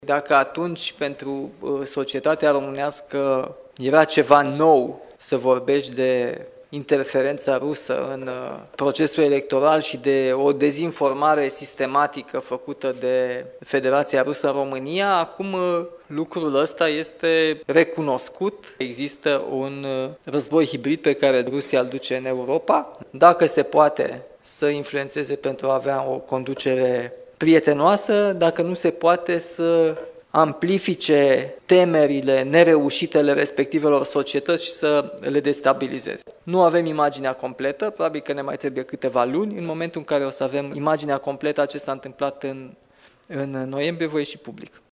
Prezent la Timișoara, președintele Nicușor Dan a spus că, față de noiembrie 2024, există mai multe elemente de context și specifice referitoare la motivele anulării alegerilor prezidențiale.